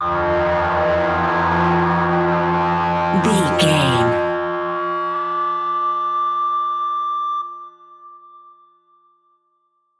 Witch Scream Low
Sound Effects
Atonal
ominous
eerie
synth
pads